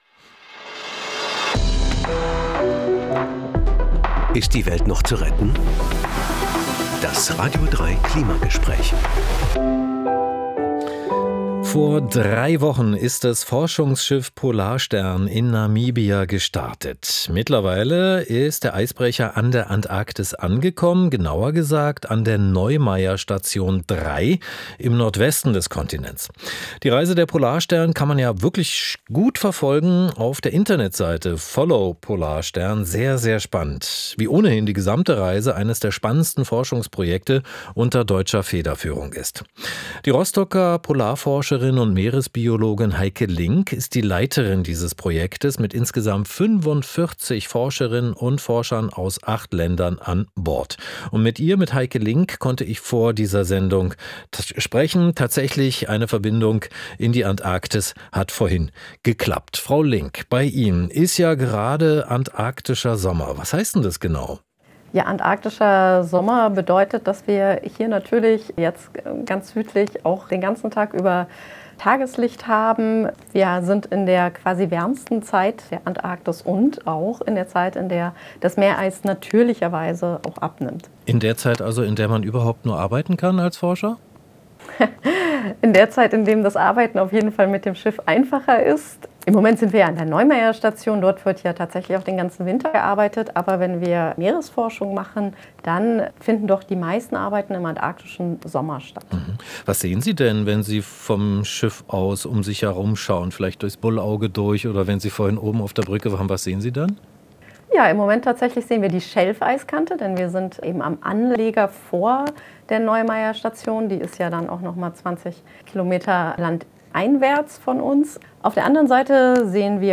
Expedition, zugeschaltet von Bord der "Polarstern".